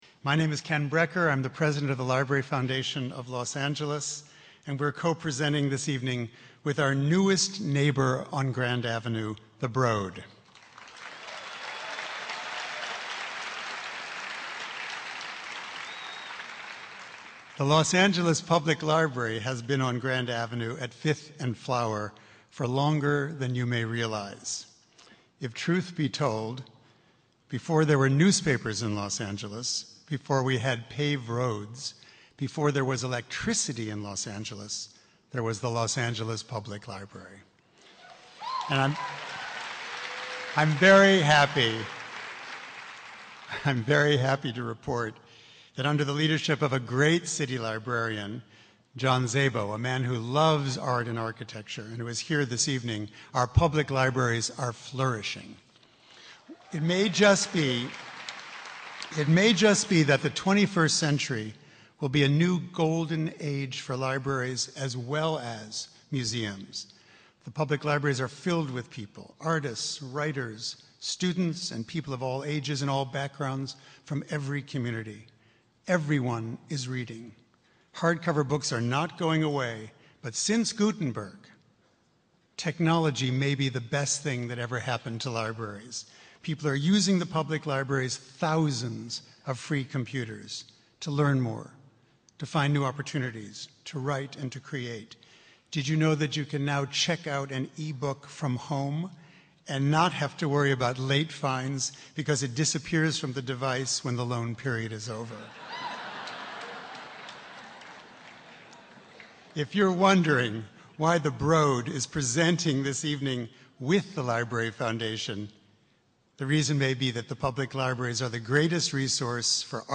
Artist Jeff Koons and filmmaker/author/photographer John Waters discuss Koon’s innovative and ever-changing art-making practice, which ranges from sculpture to painting to digital media.